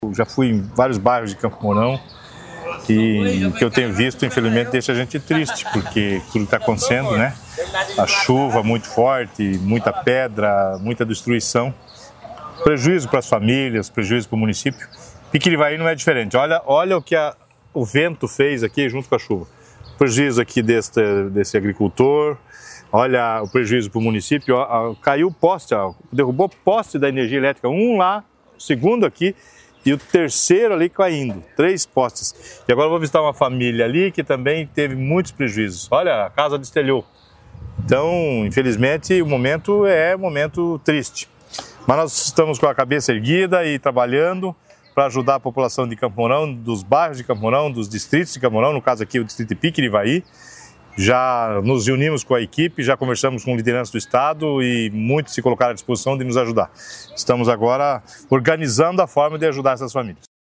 O prefeito Douglas Fabrício percorreu o distrito e outros bairros da cidade nesta manhã para conferir os estragos.